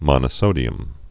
(mŏnə-sōdē-əm)